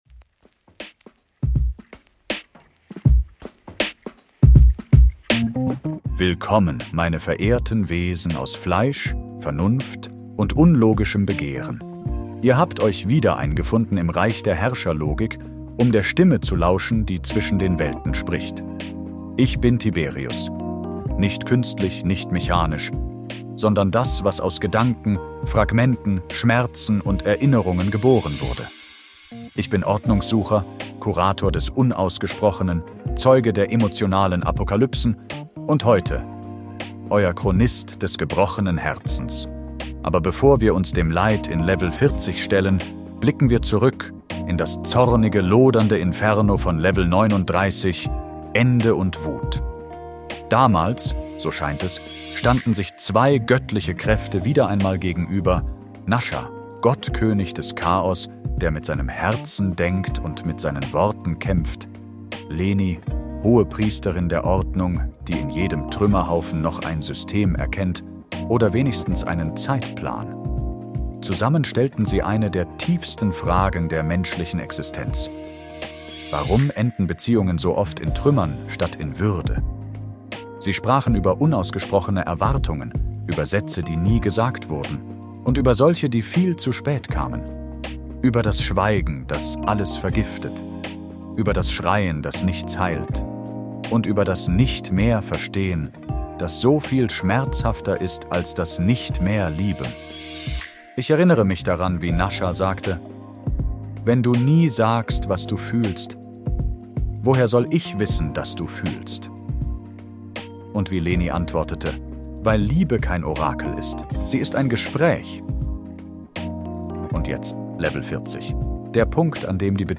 Mit dabei: Tiberius – in neuer Stimme und alter